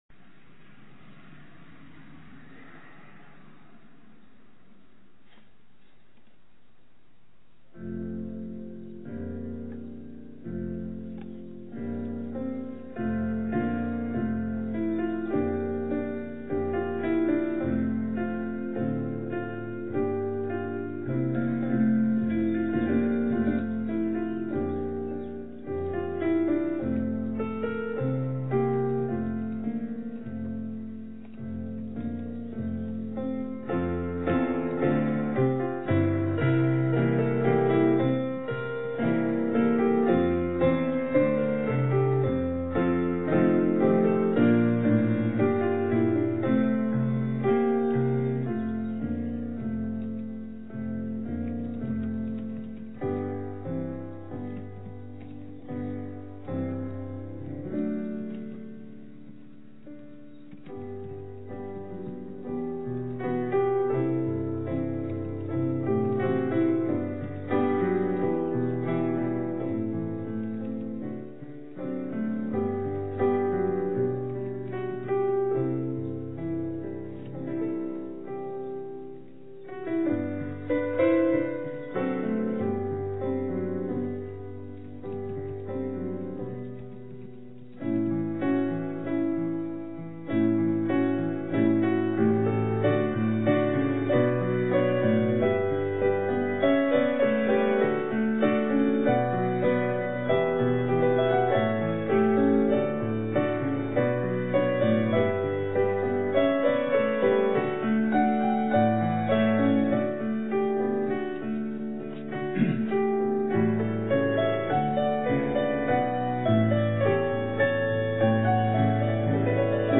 Sunday School Q&A: Dictionaries, Resources, and Research Processes